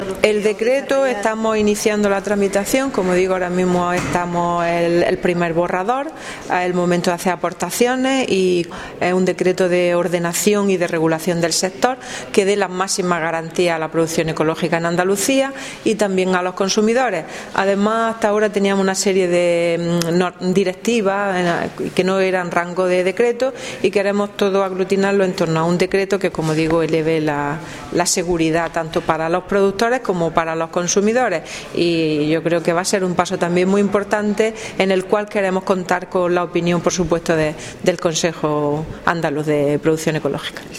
Declaraciones consejera Decreto